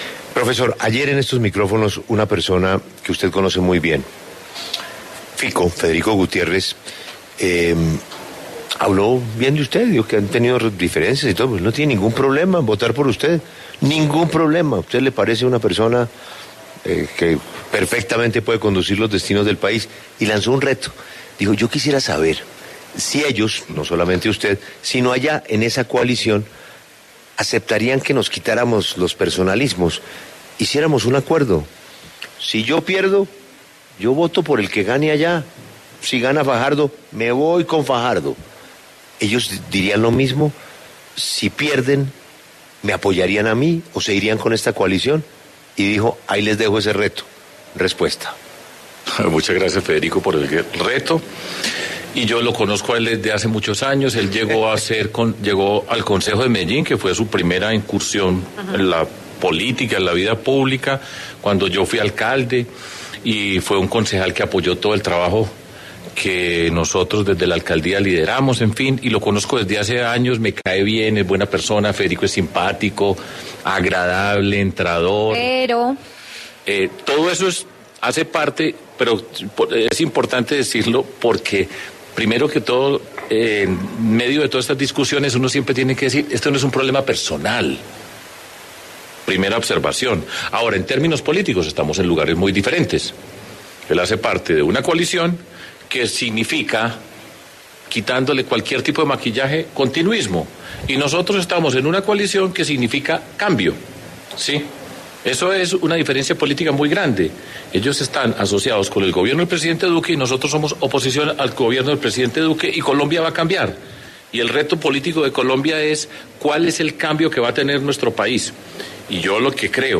En diálogo con La W, el precandidato presidencial Sergio Fajardo habló sobre su voto en blanco en las elecciones presidenciales de 2018 y su viaje a ver ballenas, lo cual molestó a muchos de sus electores.